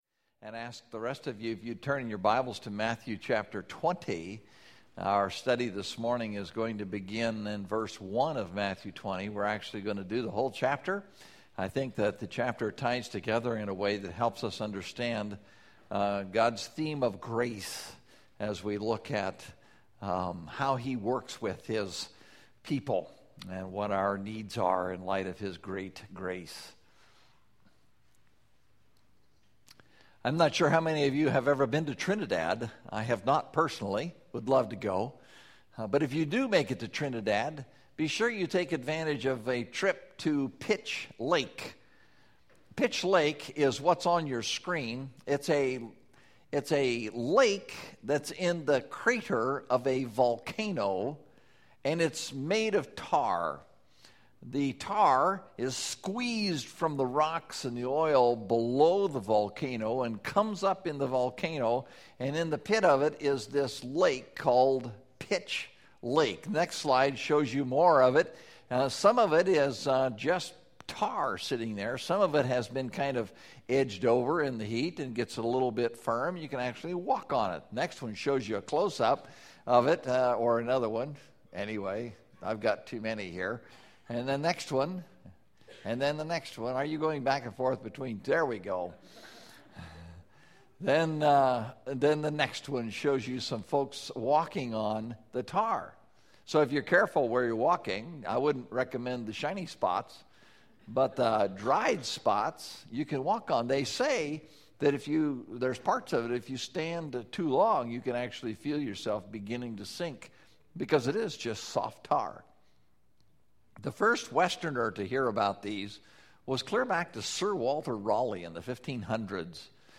Serving A Generous King (Matthew 20:1-33) – Mountain View Baptist Church